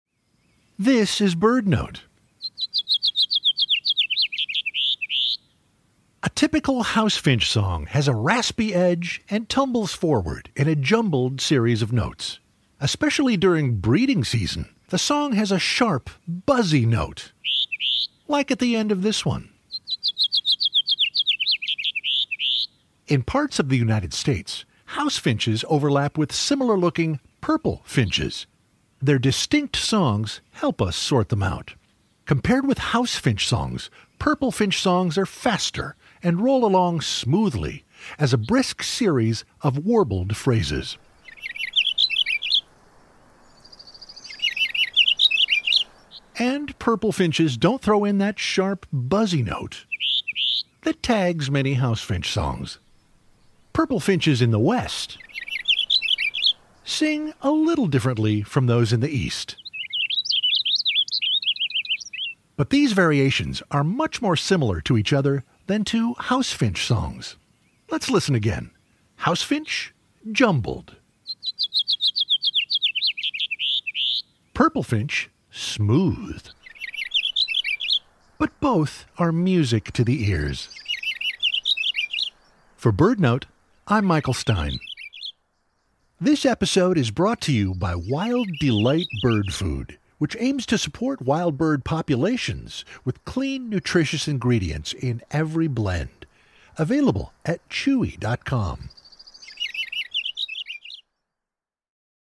Their distinct songs help us sort them out. House Finch songs are jumbled and have a sharp, buzzy note — especially during the breeding season.